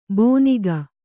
♪ bōniga